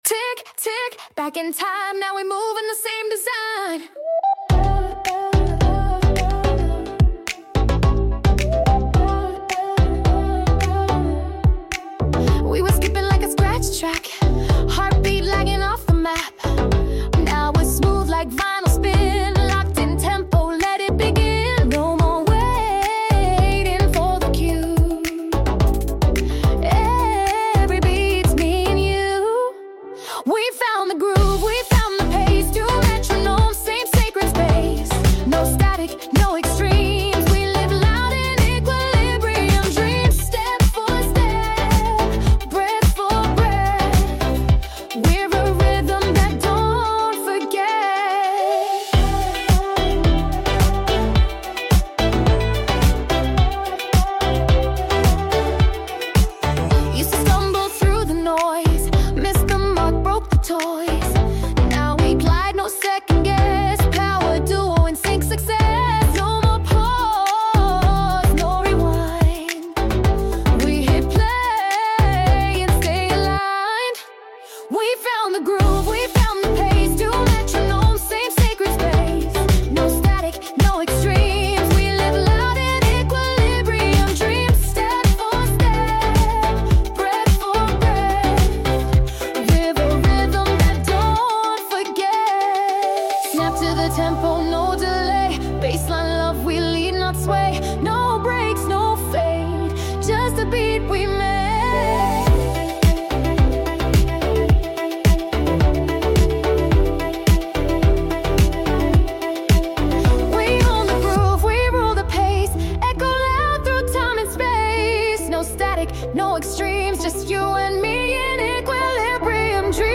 AI Sound.